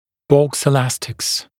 [bɔks ɪ’læstɪks][бокс и’лэстикс]межчелюстные эластичные тяги , имеющие своей целью экструзию зубов и после установки образующие прямоугольную форму